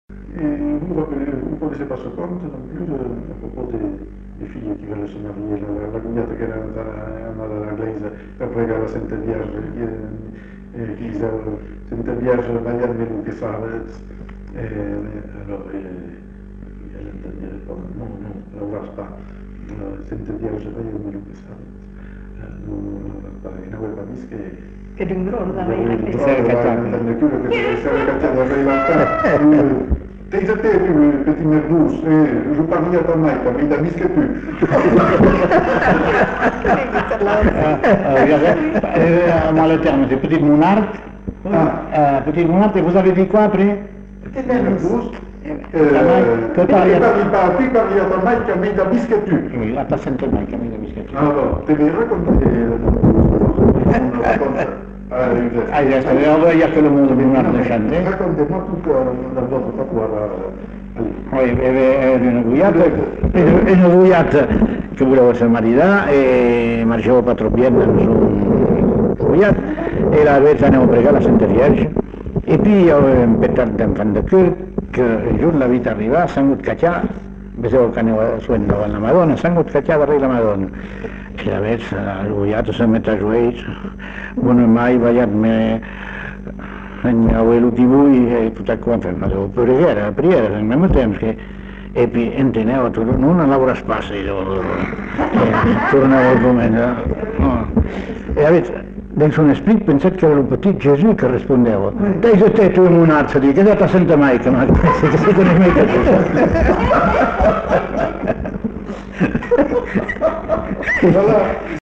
Aire culturelle : Bazadais
Lieu : Uzeste
Genre : conte-légende-récit
Effectif : 1
Type de voix : voix d'homme
Production du son : parlé